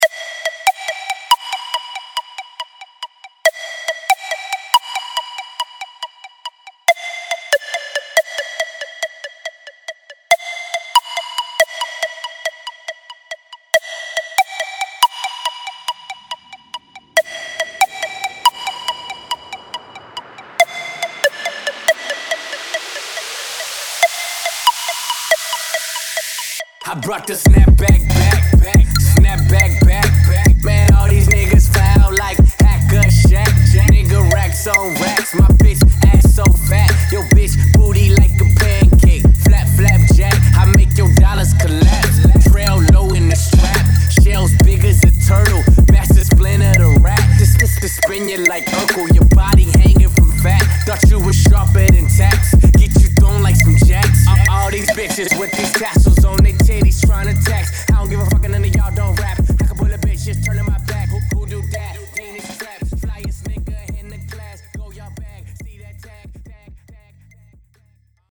вот,что получается у меня грязно